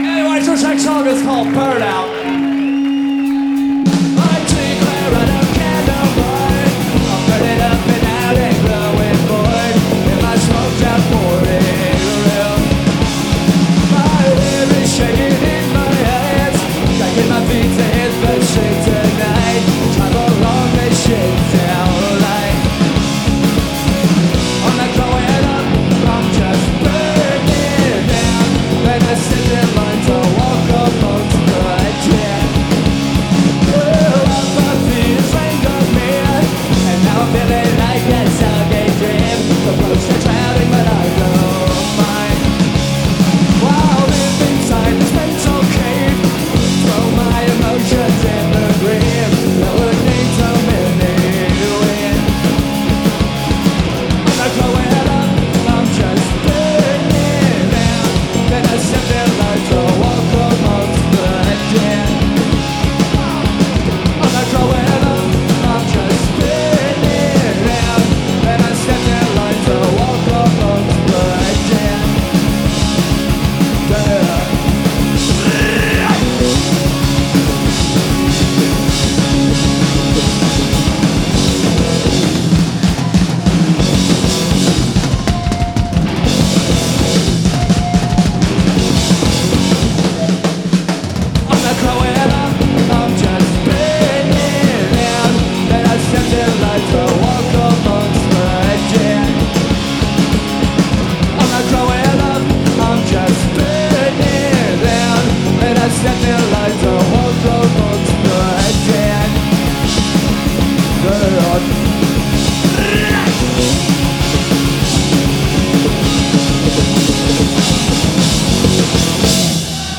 Live at Garatge Club, Barcelona 1994